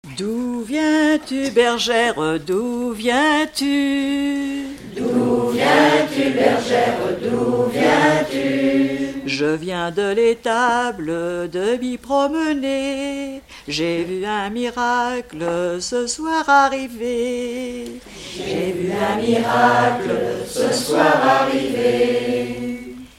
Noël
Chansons traditionnelles et populaires
Pièce musicale inédite